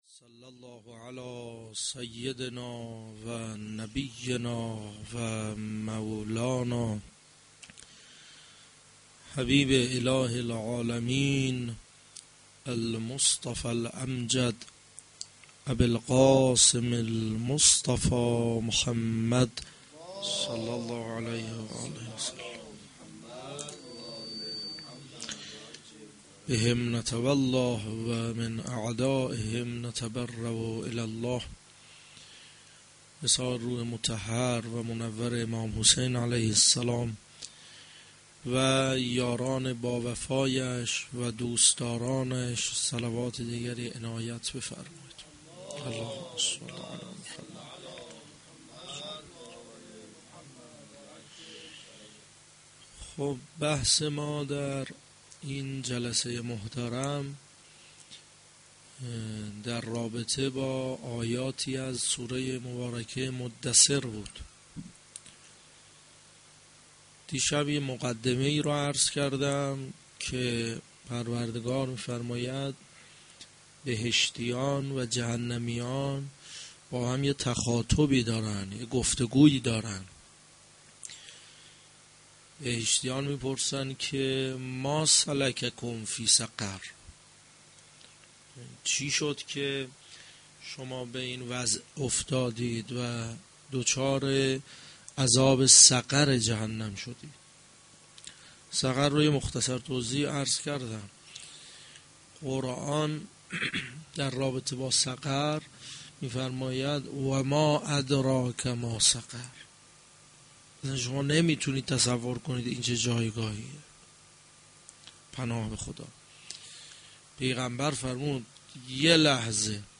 mo92-sh2-Sokhanrani.mp3